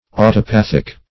Search Result for " autopathic" : The Collaborative International Dictionary of English v.0.48: Autopathic \Au`to*path"ic\, a. [See Auto- , and Pathic , a.]